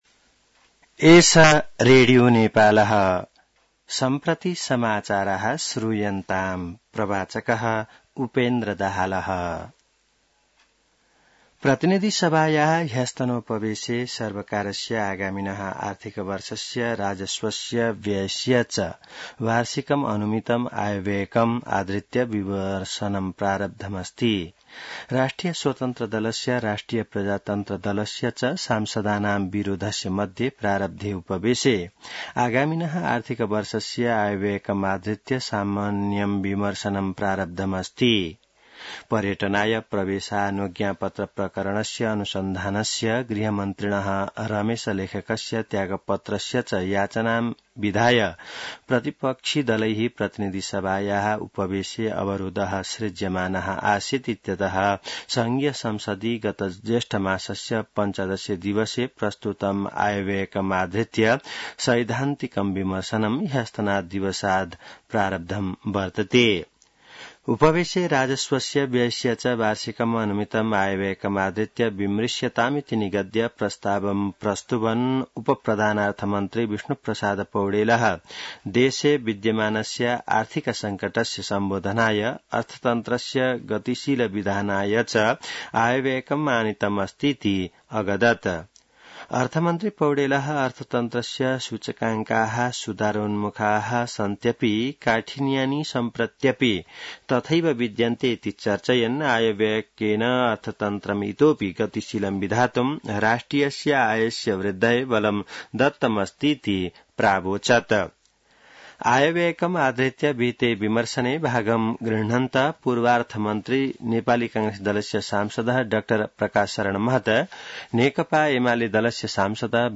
संस्कृत समाचार : २ असार , २०८२